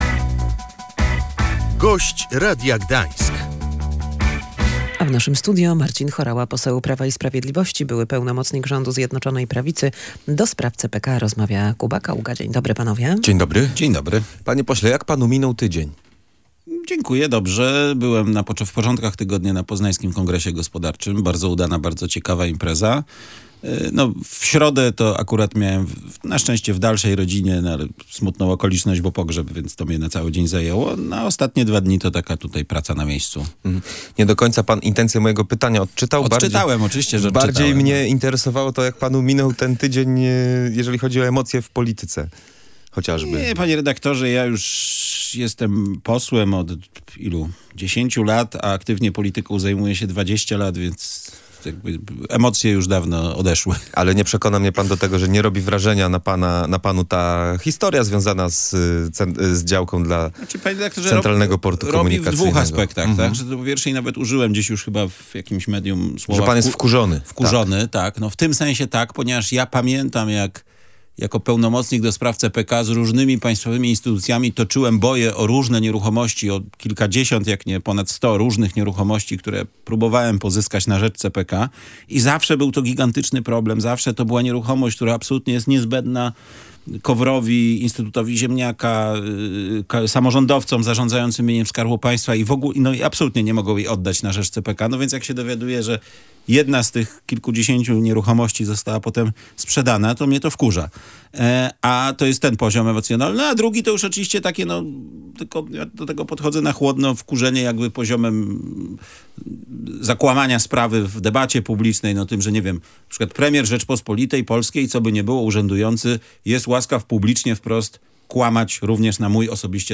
Za czasów rządów Zjednoczonej Prawicy zarząd Centralnego Portu Komunikacyjnego zachował się prawidłowo i dążył do zablokowania sprzedaży działki – przekonywał w Radiu Gdańsk były pełnomocnik rządu ds. budowy CPK Marcin Horała.